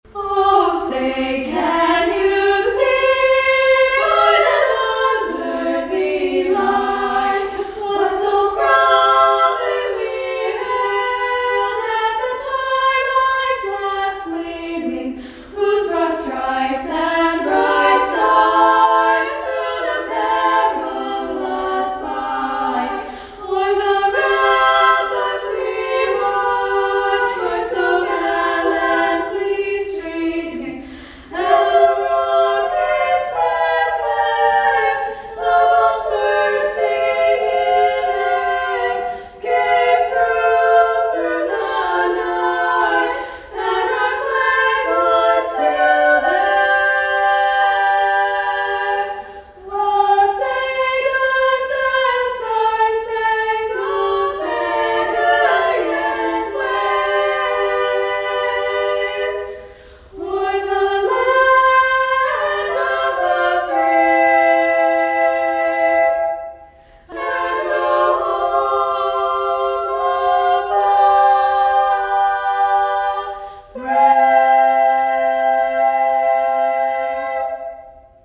Congratulations to the quartet from Beckman Catholic High School, Dyersville, for winning DuTrac’s annual “Oh Say Can You Sing” National Anthem Showcase!